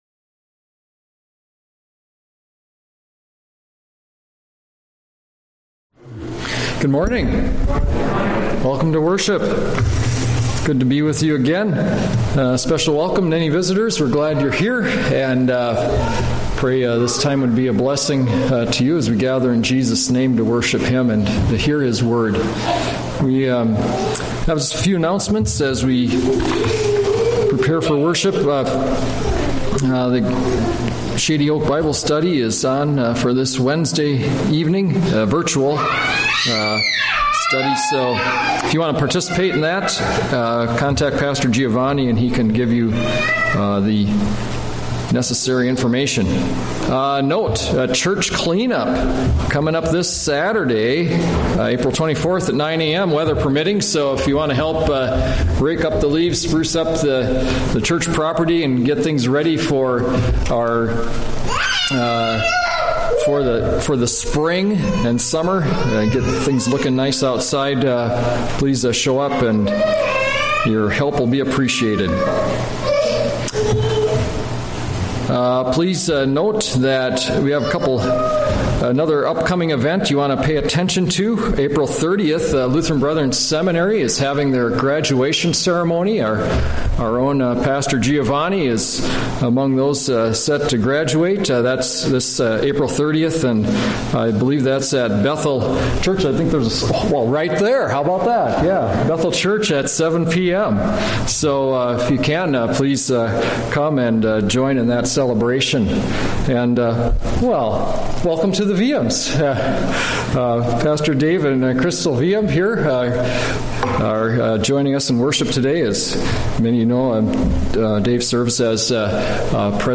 A message from the series "Sunday Worship." What About Doubt? - Luke 24:36-49